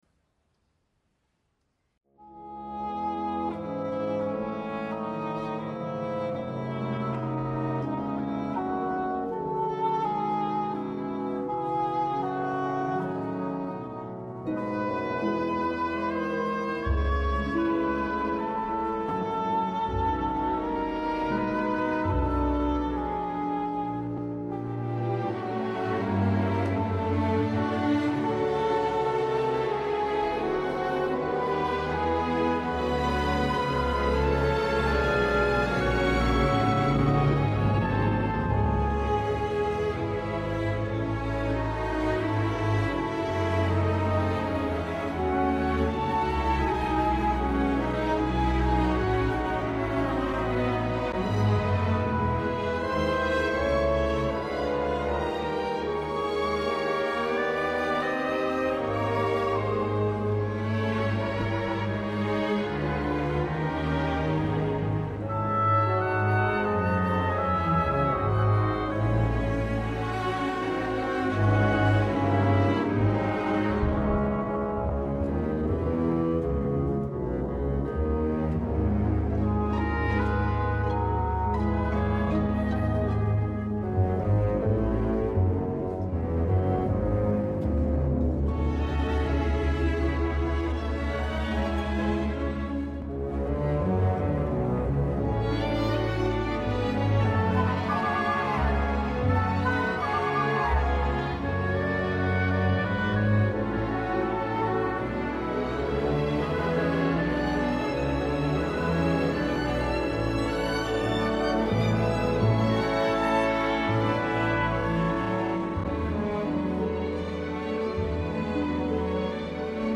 in diretta – Concerto Spirituale del Venerdì Santo